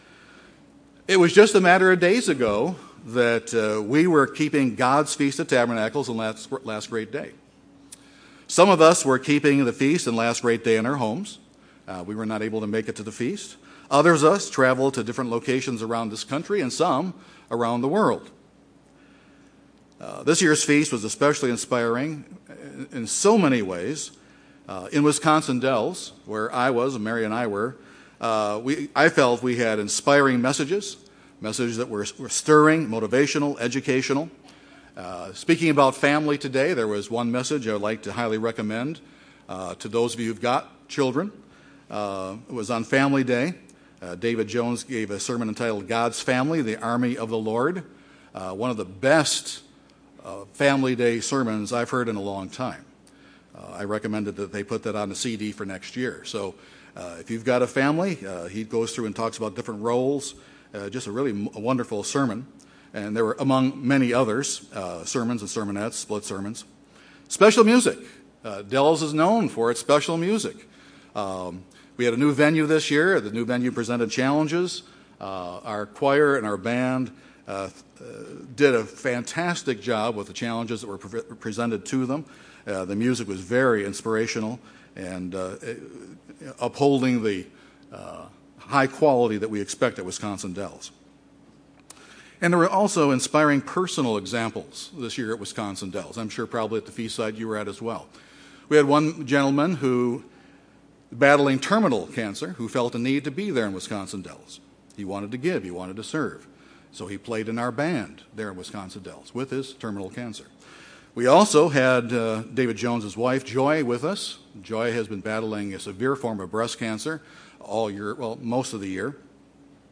Sabbath Services benefits holy convocations Transcript This transcript was generated by AI and may contain errors.